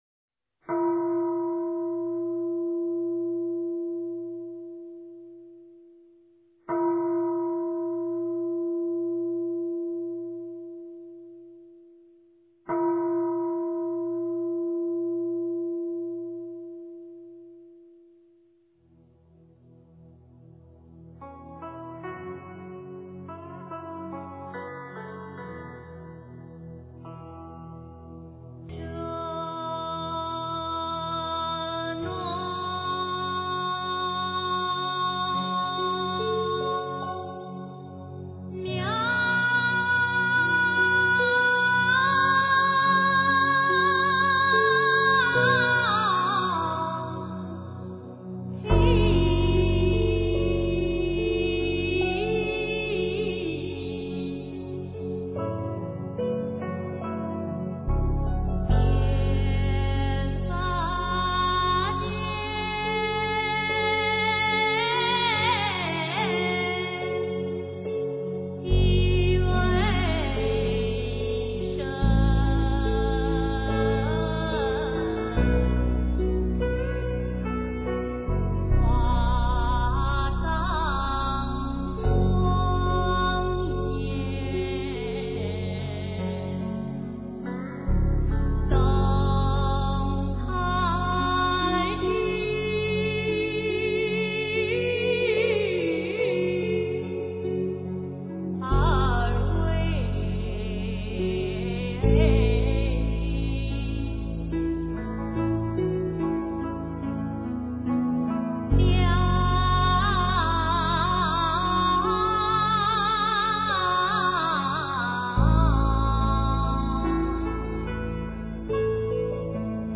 真言
佛音 真言 佛教音乐 返回列表 上一篇： 六字断除六道苦难颂--佛音 下一篇： 百字明咒--唱经给你听 相关文章 就业工作祈愿文 就业工作祈愿文--星云大师...